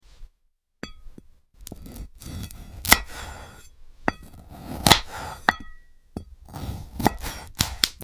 ASMR cutting tomato 🍅 sound effects free download